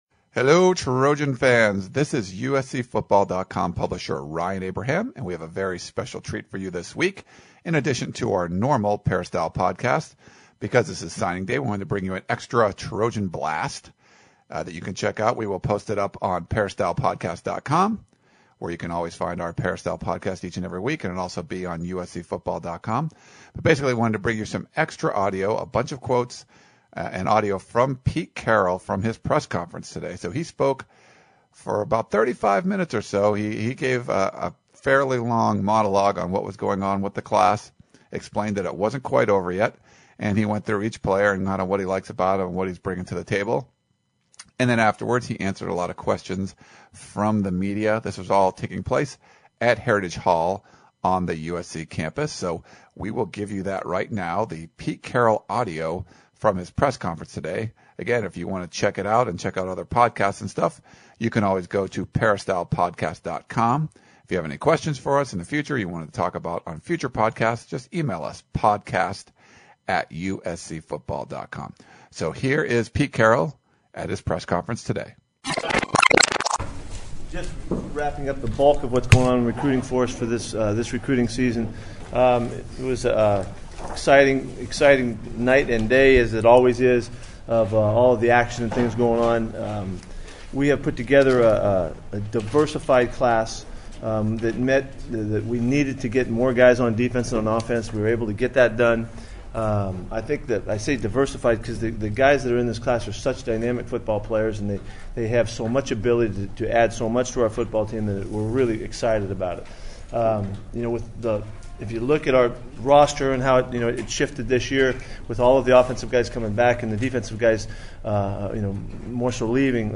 The Trojan Blast is a special signing day edition of the Peristyle Podcast that describes the new USC recruiting class. In this Trojan Blast we bring you all of the audio from the Wednesday press conference held by USC head coach Pete Carroll. In it he discusses the tremendous class of 2009 signed by the Trojans and answers plenty of questions from the media.